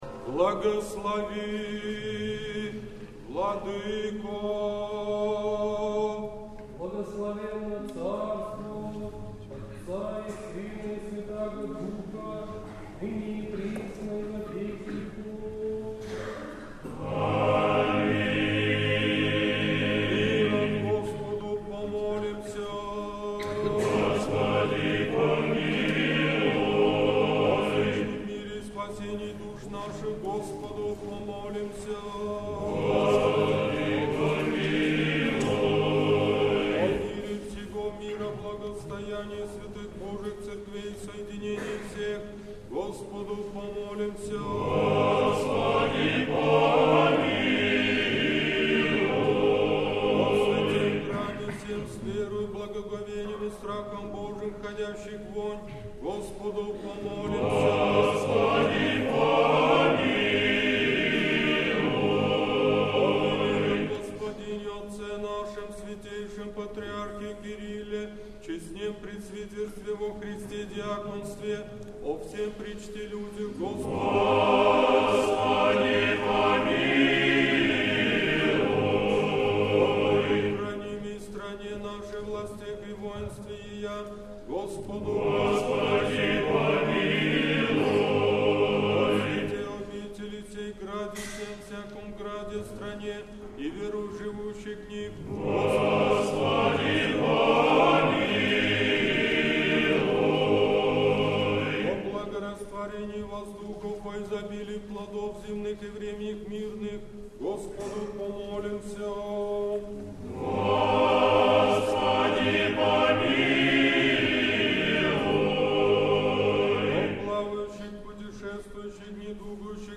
Божественная литургия в Неделю 24-ю по Пятидесятнице в Сретенском монастыре
Божественная литургия. Хор Сретенского монастыря.